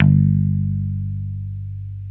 Index of /90_sSampleCDs/Roland LCDP02 Guitar and Bass/GTR_Dan Electro/BS _Dan-O Bass